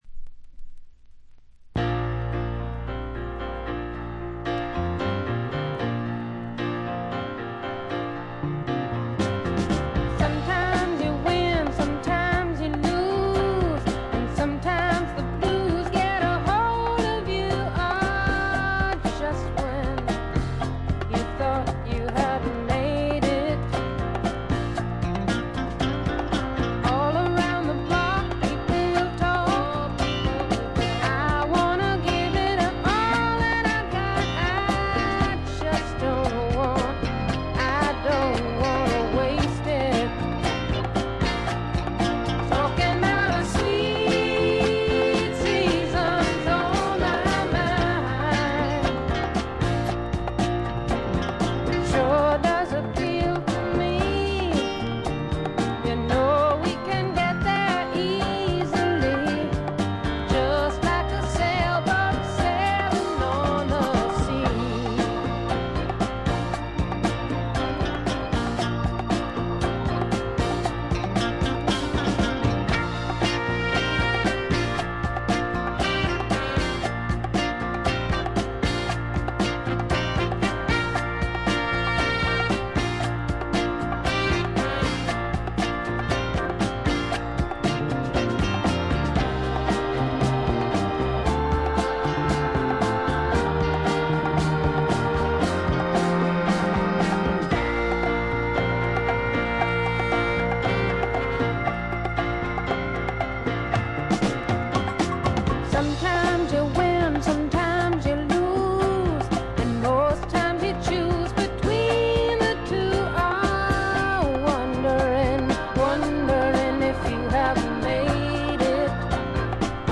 チリプチ少々（A1フェードアウトの消え際とか）。
初期仕様クアドラフォニック4チャンネル盤。
試聴曲は現品からの取り込み音源です。